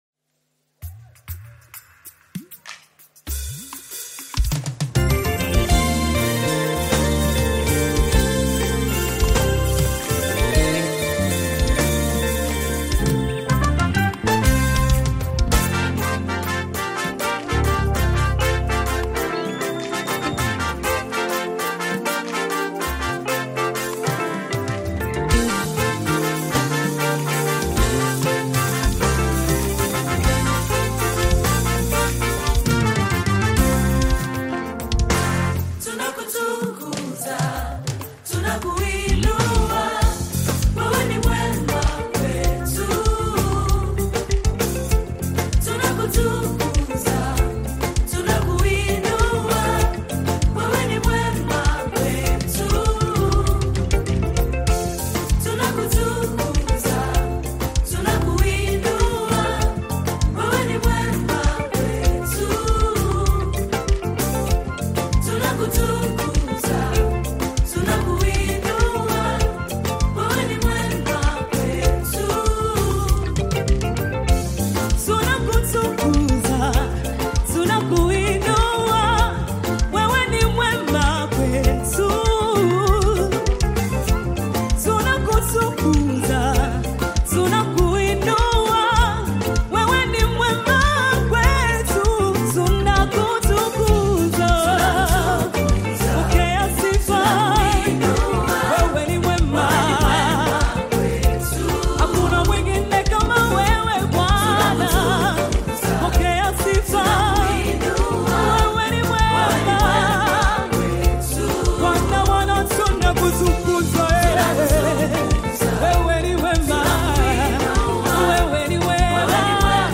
African Music
One of the best Tanzanian worship teams
worship song